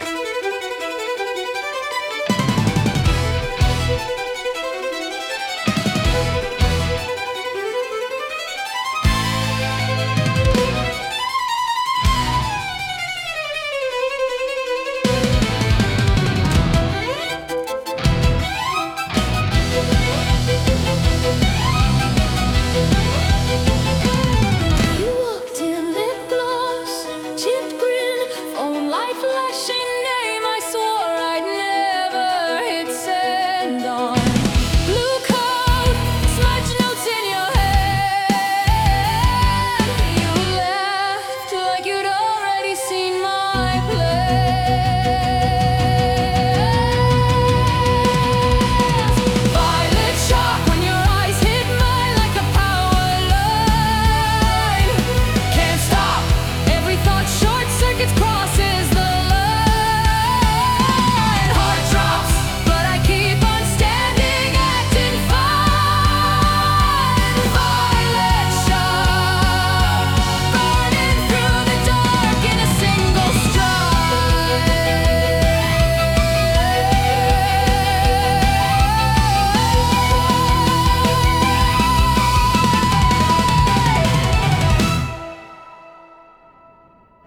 激しく刻まれる重厚なドラムと、疾走感あふれるバイオリンの旋律。
そこに魂を揺さぶる女性ボーカルの力強い歌声合わさった、圧倒的にクールでドラマチックな一曲です。
息つく暇もない高速テンポは、次々と難度の高い技を繰り出し、観客を惹きつけるアグレッシブな演技にぴったり。